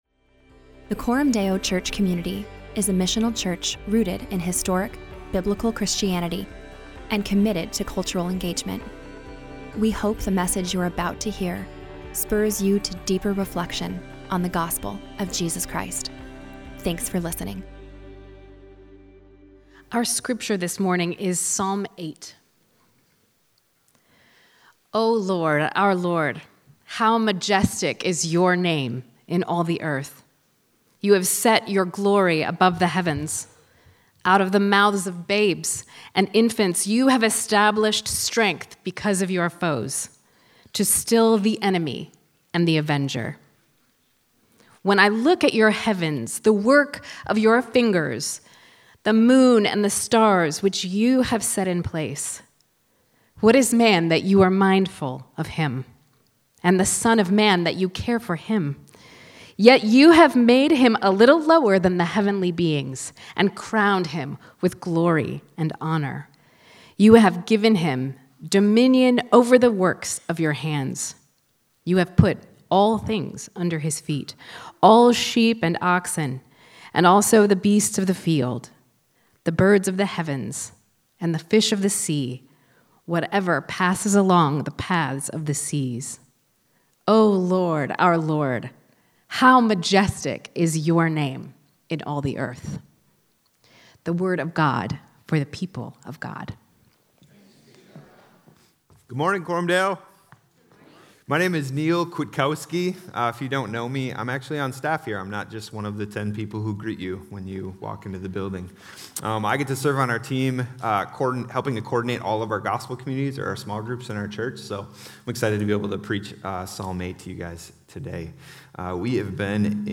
So every summer, the people of Coram Deo dwell in the Psalms - the worship songbook of God’s people - to learn the language and practices of Christian worship. This preaching series is a progressive journey